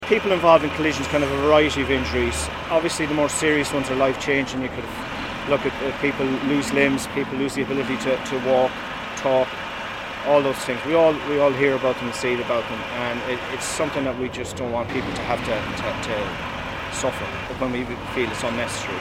Speaking at a briefing in Kilcock